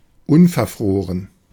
Ääntäminen
IPA : /'bɹeɪz(ə)n/